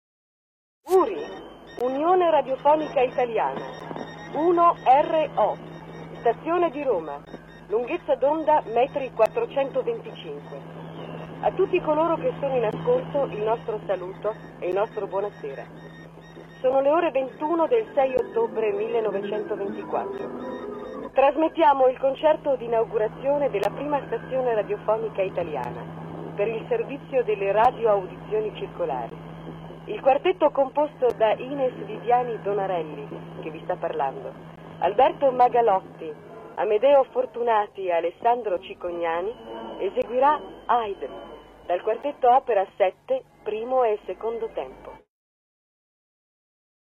ricostruzione sonora moderna perchè all’epoca non era possibile alcuna registrazione).